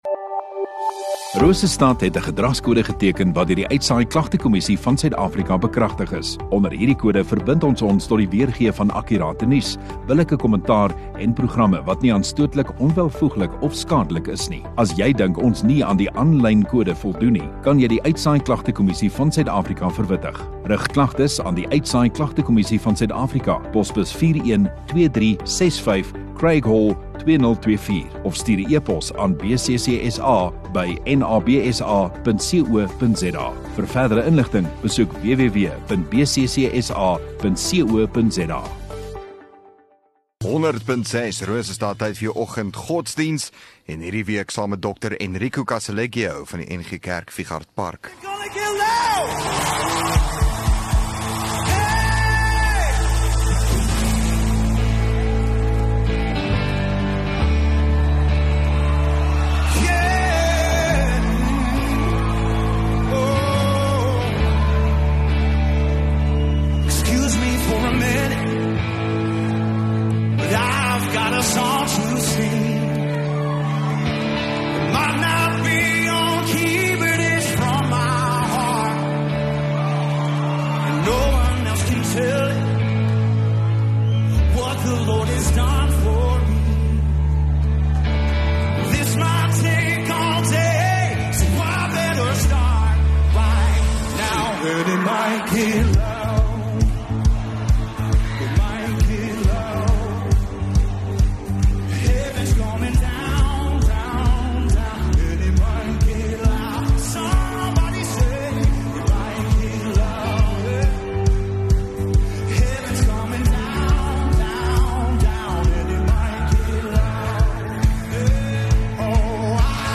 6 May Dinsdag Oggenddiens